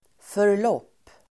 Uttal: [för_l'åp:]